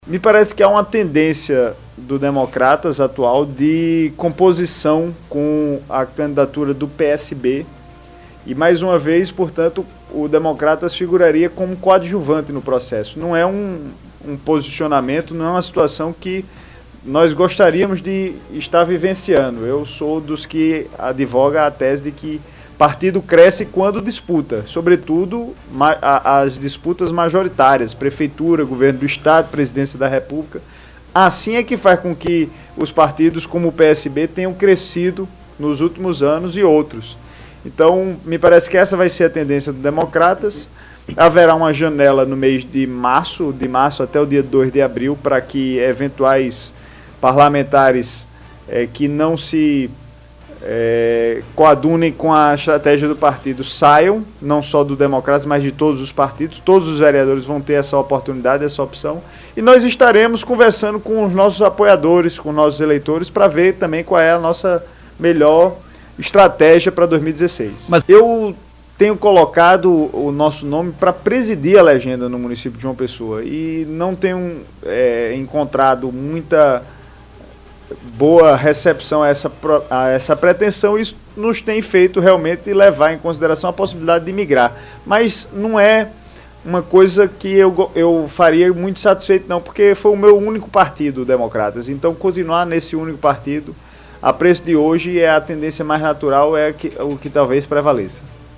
Durante entrevista que aconteceu na tarde de hoje (03), no programa Debate Sem Censura, da rádio Sanhauá, o vereador Lucas de Brito do Democratas falou sobre suas expectativas na CMJP neste ano de eleição.